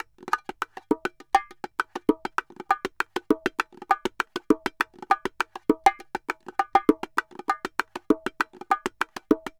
Bongo_Salsa 100_2.wav